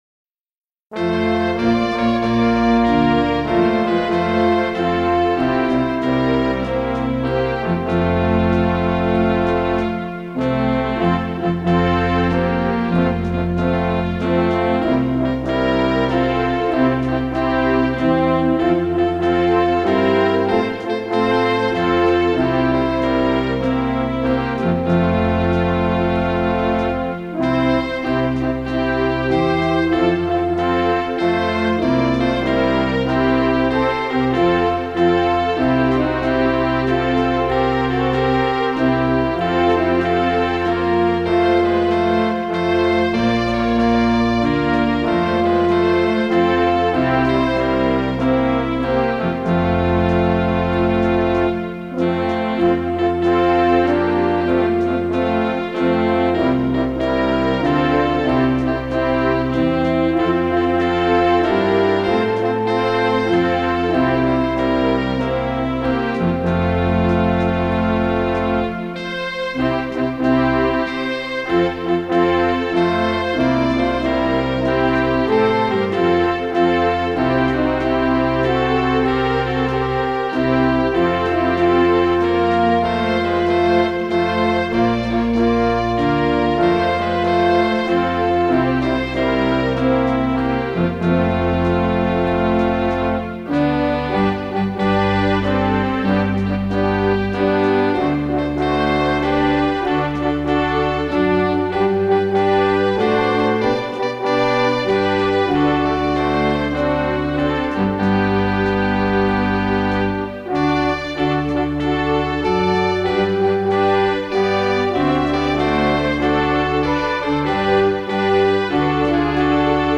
Instrumental accompaniment